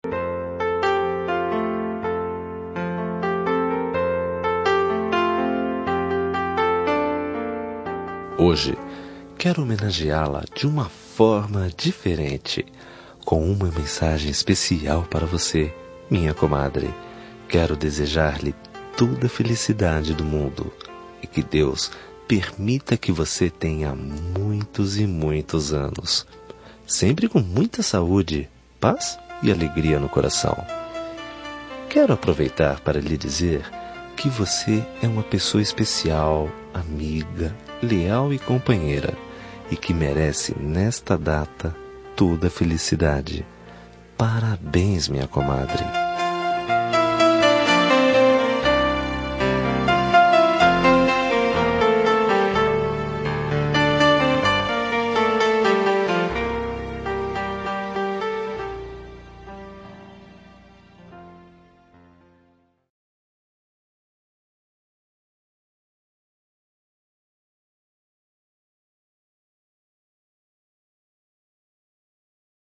Aniversário de Comadre – Voz Masculina – Cód: 202161